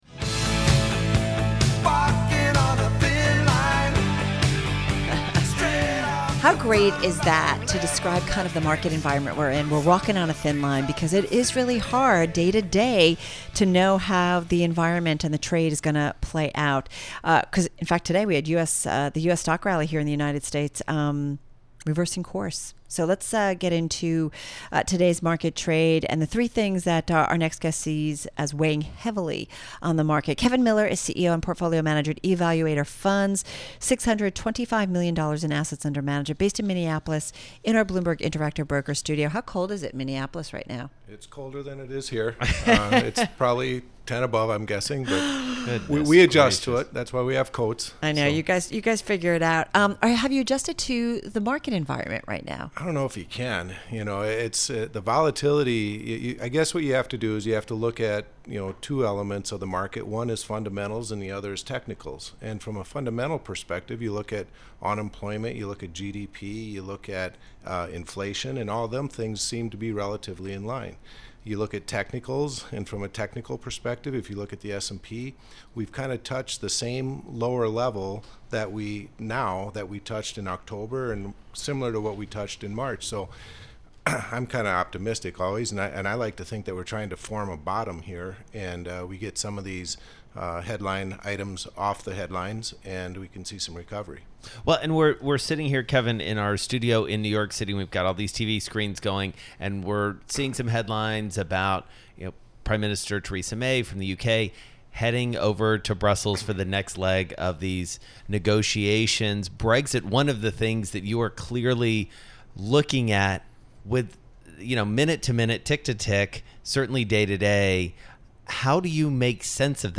in studio with Bloomberg radio talking market volatility, Brexit and stock picks.